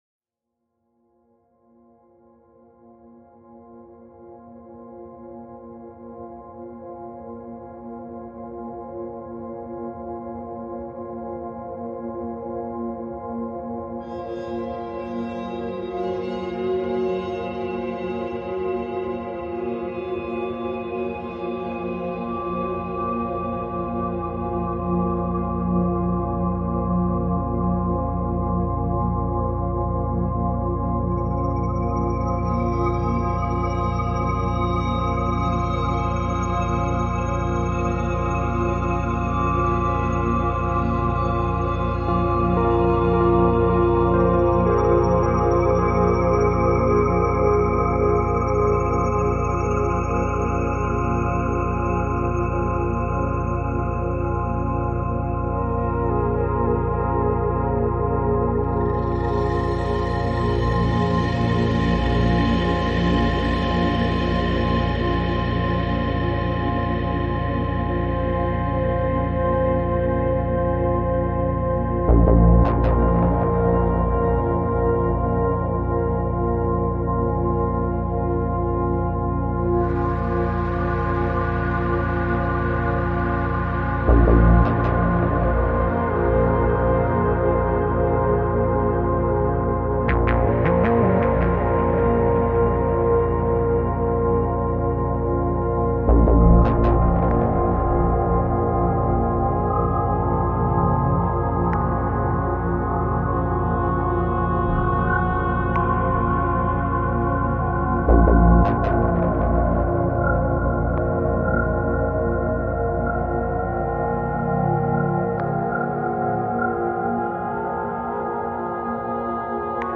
Downtempo, Ambient, Psychill